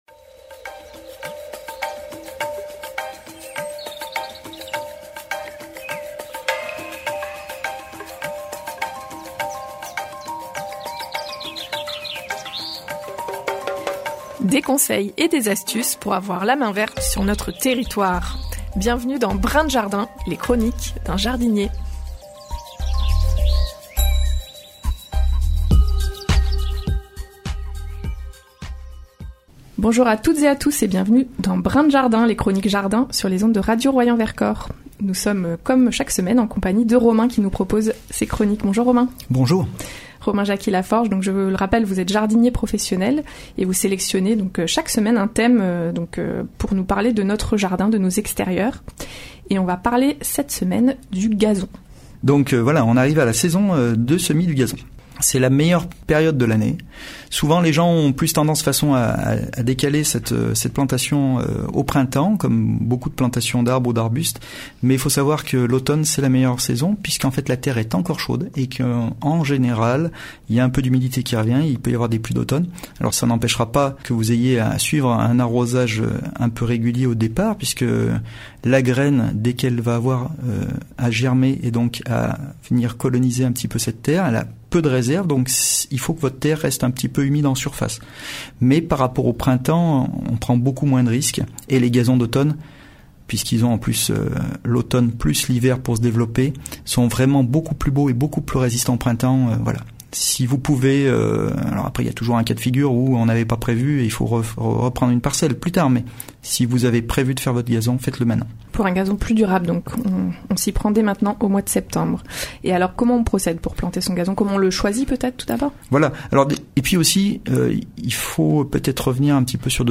La nouvelle chronique hebdomadaire sur les ondes de Radio Royans Vercors